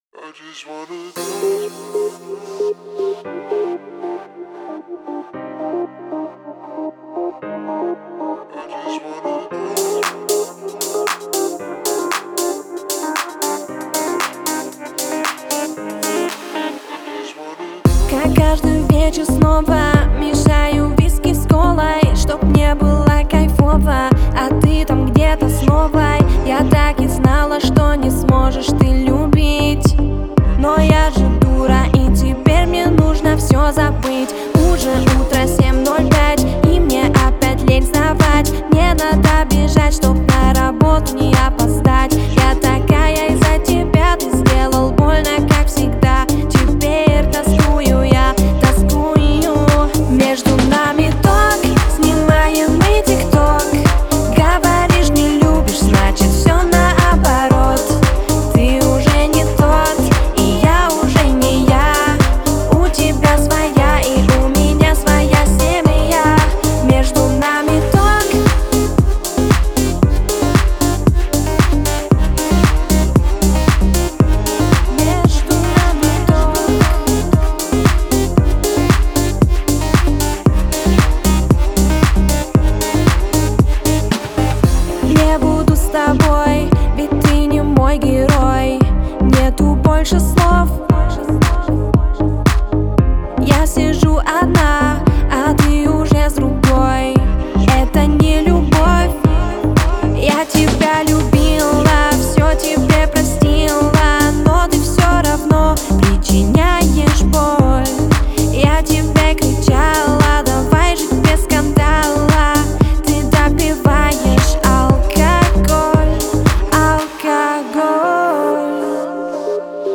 яркая и энергичная композиция в жанре поп-рок